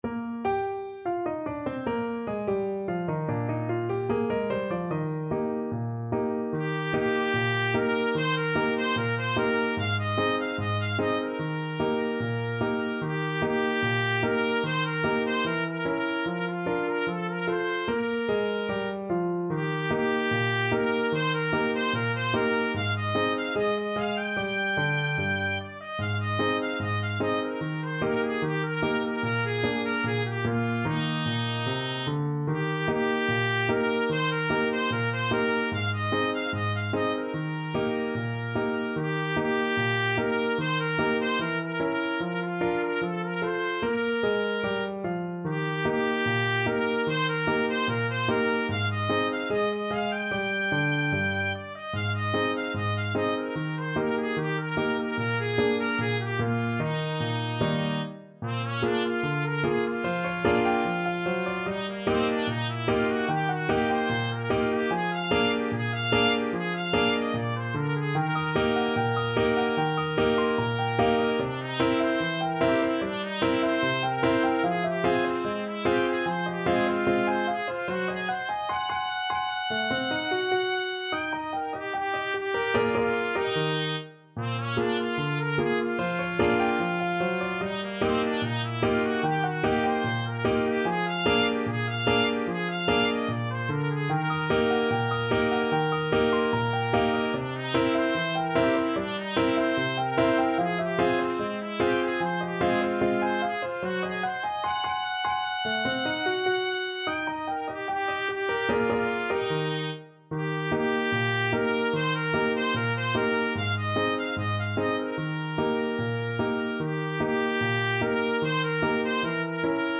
Trumpet
Eb major (Sounding Pitch) F major (Trumpet in Bb) (View more Eb major Music for Trumpet )
Not Fast = 74
2/4 (View more 2/4 Music)
Jazz (View more Jazz Trumpet Music)